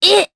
Cleo-Vox-Deny_jp_c.wav